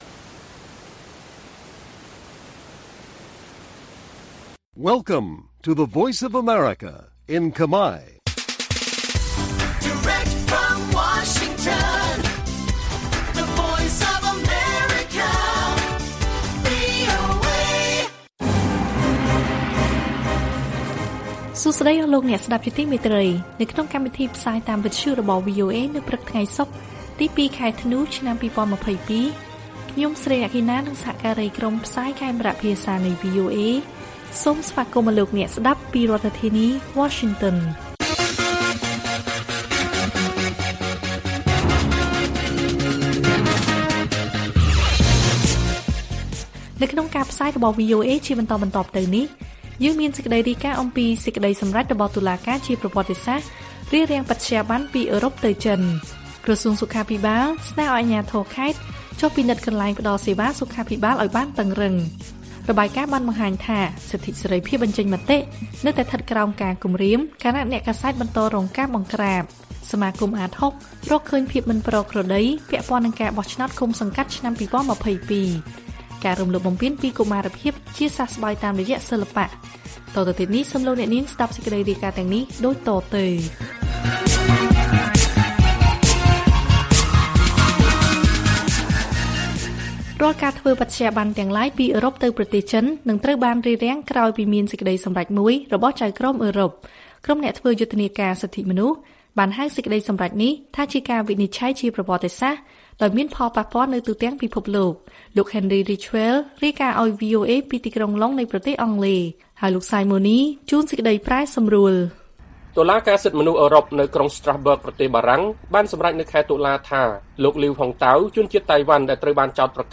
ព័ត៌មានពេលព្រឹក ២ ធ្នូ៖ សេចក្តីសម្រេចតុលាការជាប្រវត្តិសាស្ត្ររារាំងបត្យាប័នពីអឺរ៉ុបទៅចិន